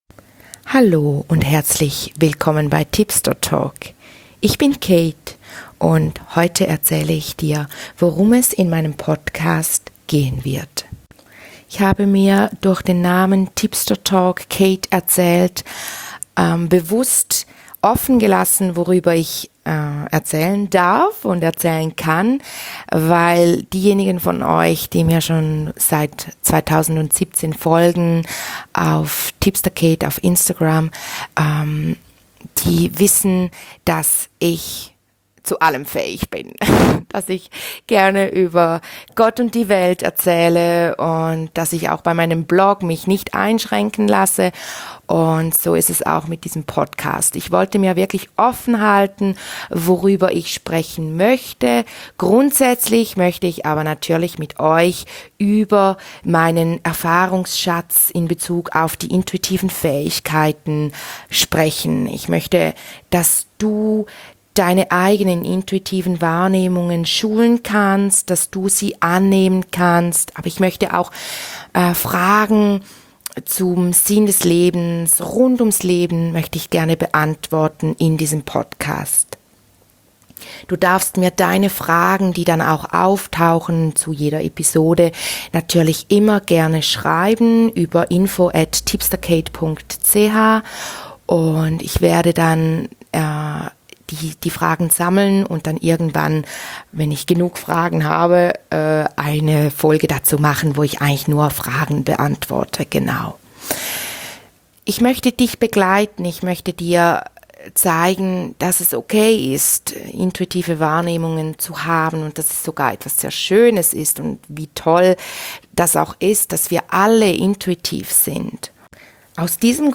Dein Schweizerdeutscher Podcast für mehr Energie & Harmonie im Leben!